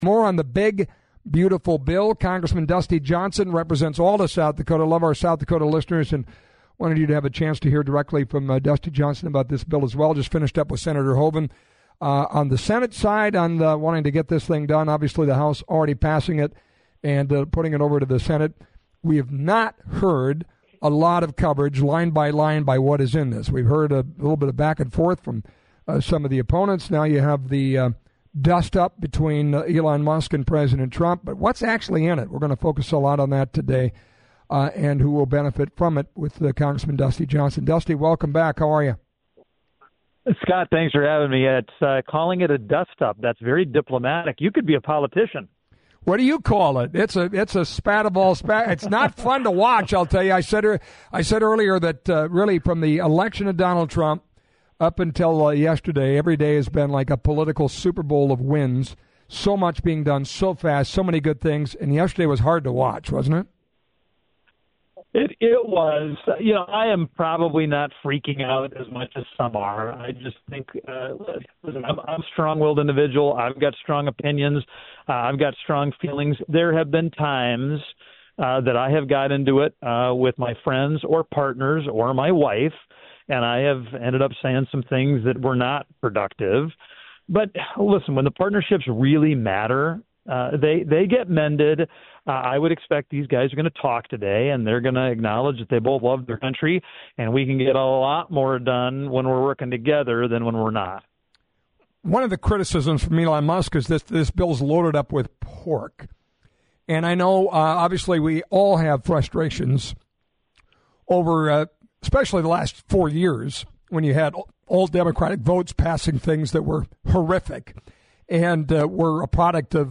Rep. Dusty Johnson’s conversation